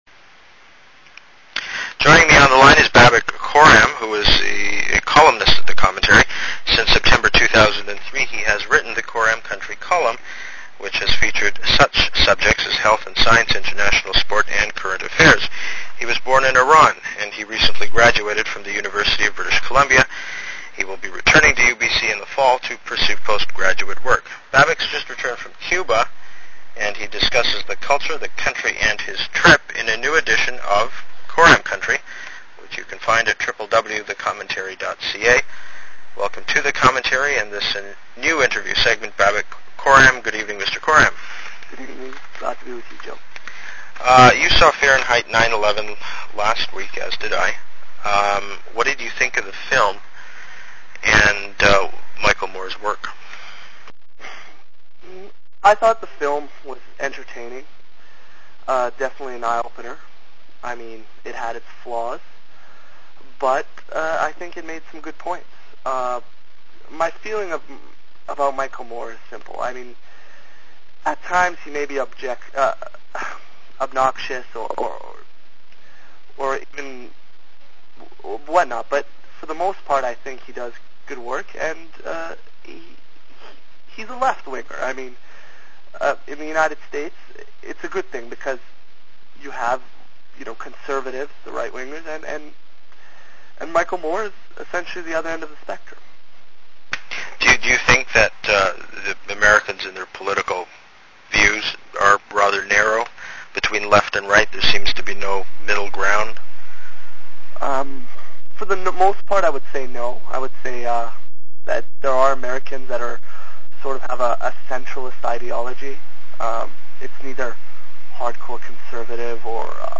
Text of introduction